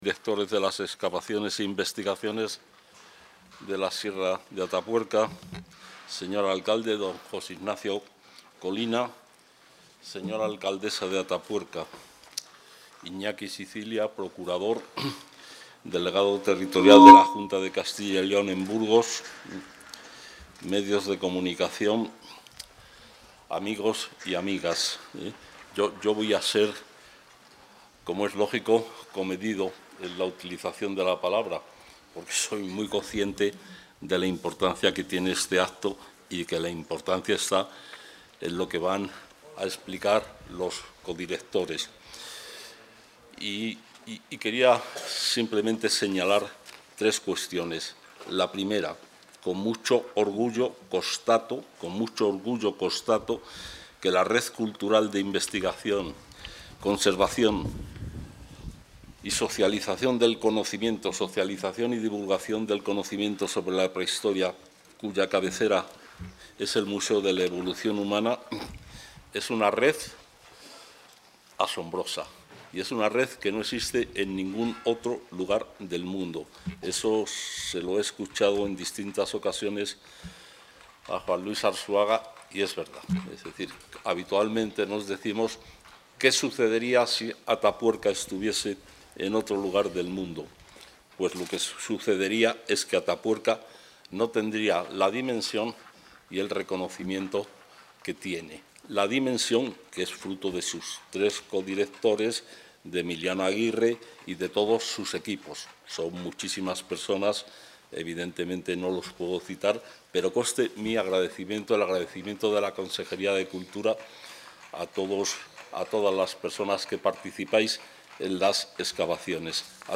El consejero de Cultura, Turismo y Deporte, Gonzalo Santonja, ha participado hoy en la rueda de prensa con la que se clausura la campaña de...
Intervención del consejero.